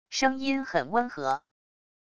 声音很温和wav音频